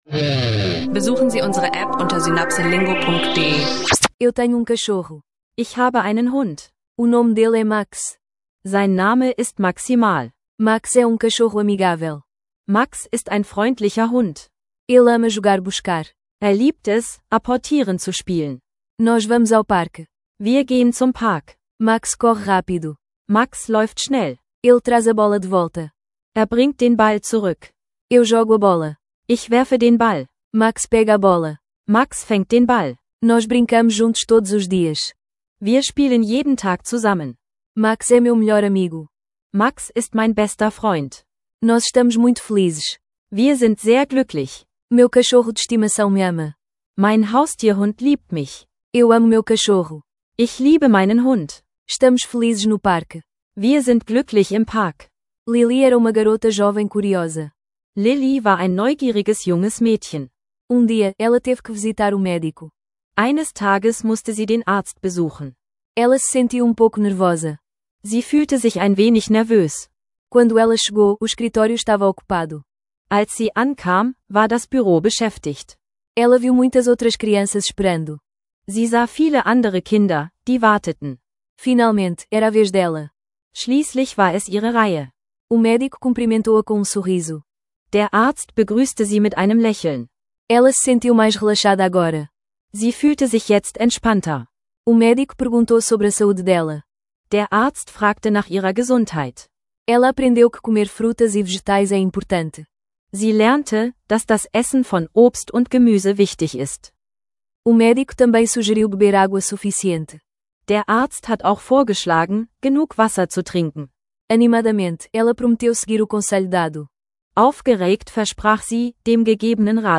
Entdecken Sie die besten Übungen zum Portugiesisch lernen für Anfänger mit unserem interaktiven Audio Sprachkurs.